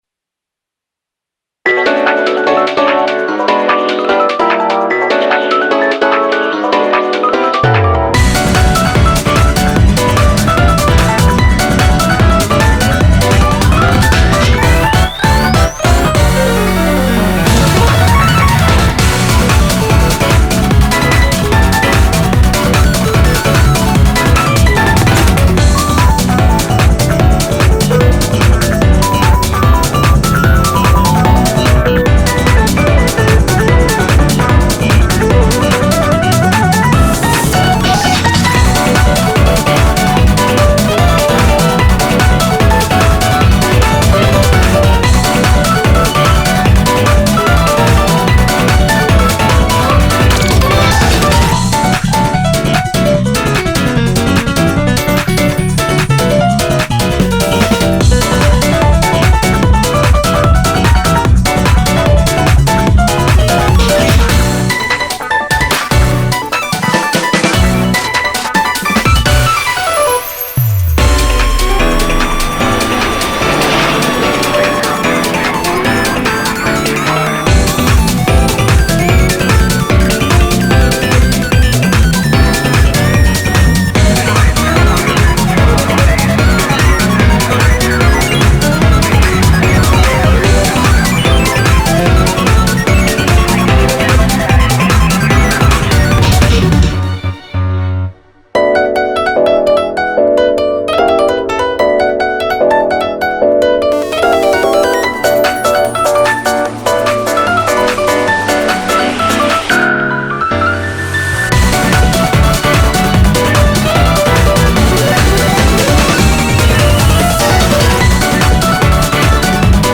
BPM148
GENRE: SPY ACTION/HARD LOUNGE